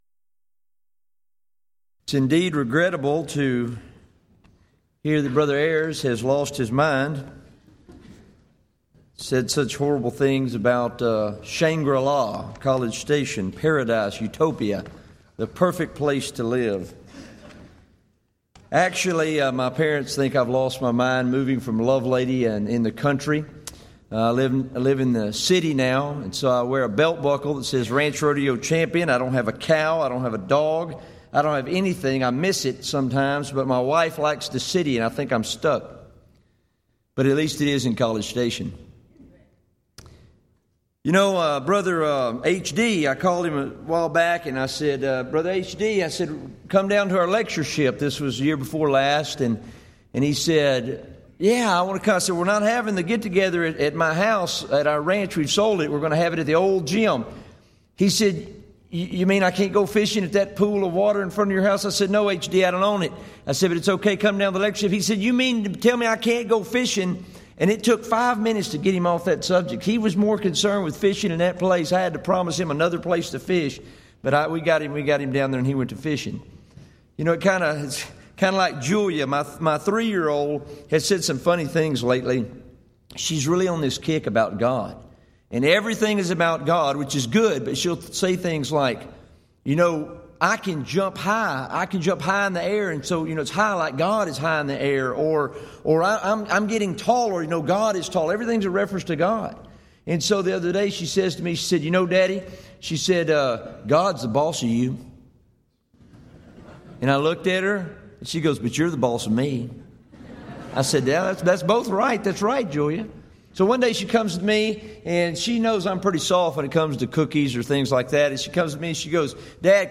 Event: 21st Annual Gulf Coast Lectures
lecture